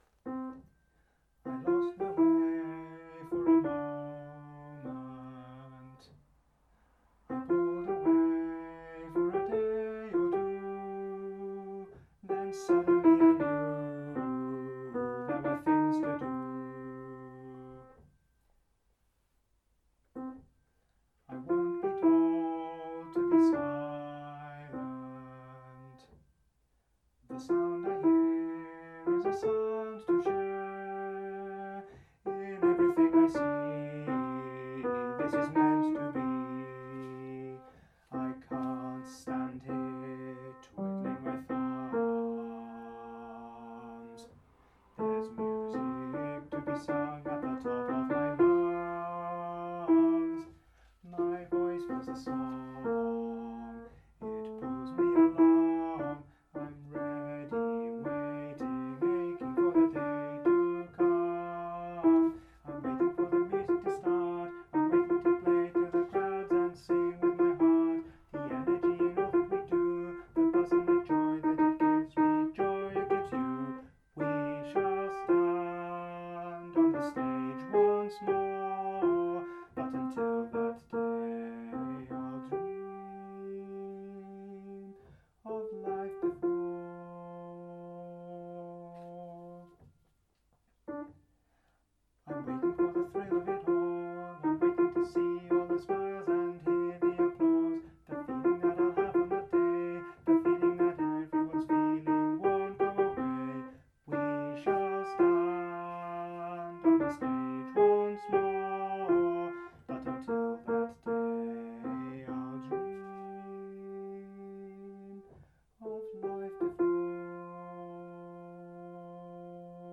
Alto track for practice (ditto)
alto.mp3